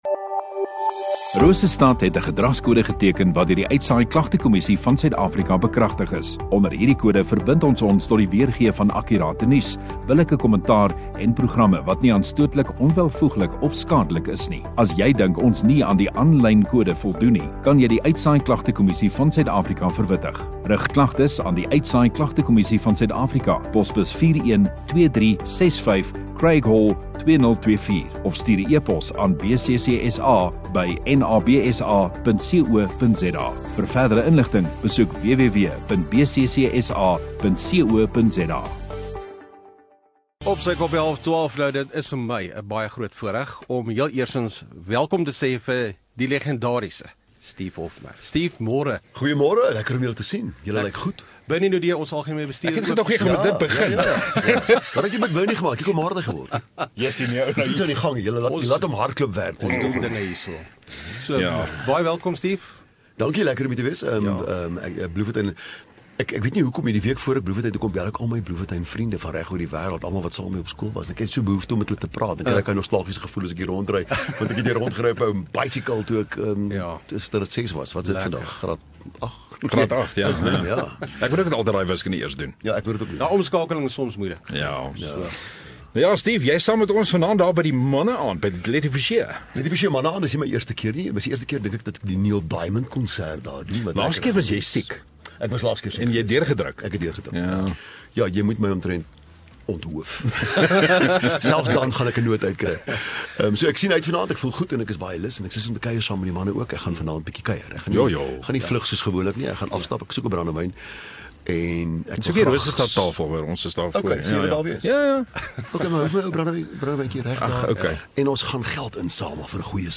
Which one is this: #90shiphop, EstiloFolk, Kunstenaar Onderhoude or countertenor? Kunstenaar Onderhoude